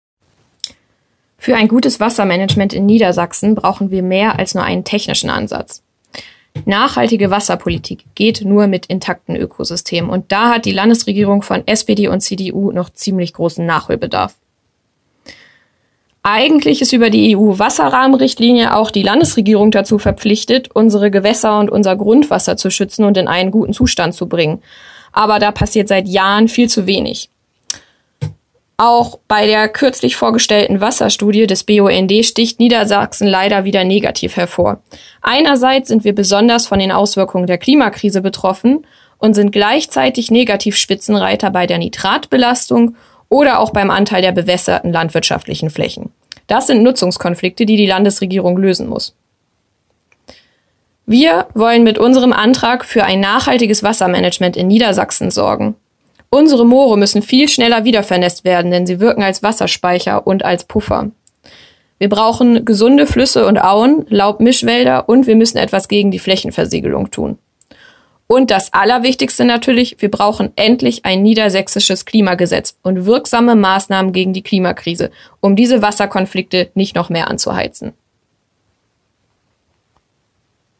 Audio-Statement Imke Byl zur Wasserpolitik